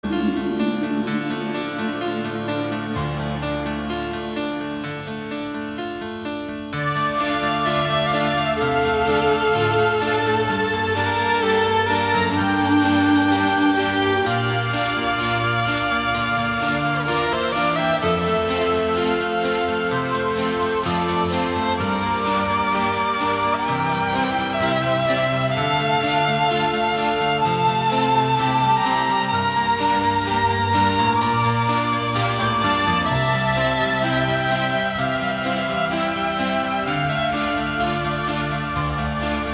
• Качество: 192, Stereo
без слов
русский шансон
блатные